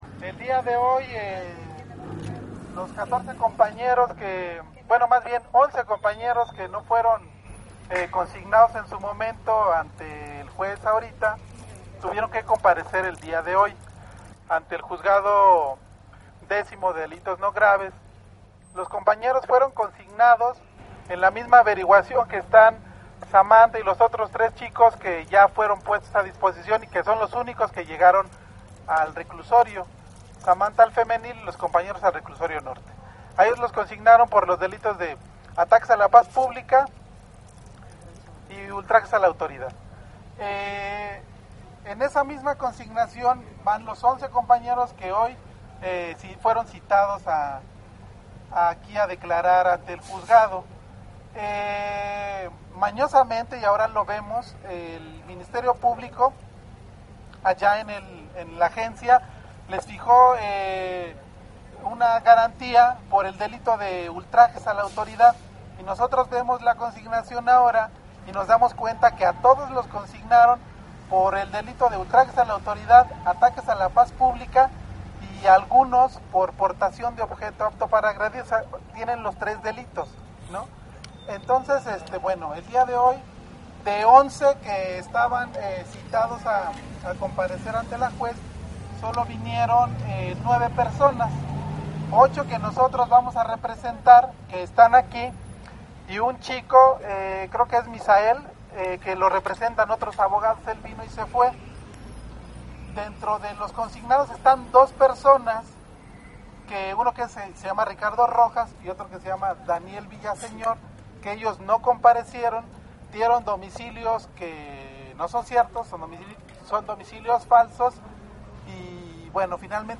A la salida de dicha comparecencia, un compañero del colectivo de Abogados Zapatistas dieron un informe de la situación de 8 de los compañeros que son a quienes ellos están representando, informo que la jueza cuenta con tres días para definir cuál será la situación jurídica de los compañeros, y que lo que esperan es un auto de formal prisión, ya que es clara la consigna que hay en contra de los compañeros de seguir con este proceso jurídico basándose en el dicho de los policías, que por cierto "son falsas, son en un mismo sentido, son de machote copiaron y pegaron, solo cambiaron el nombre y el número de placa, pero son las mismas" comenta el abogado.
Audio del abogado solidario del Colectivo de Abogados Zapatistas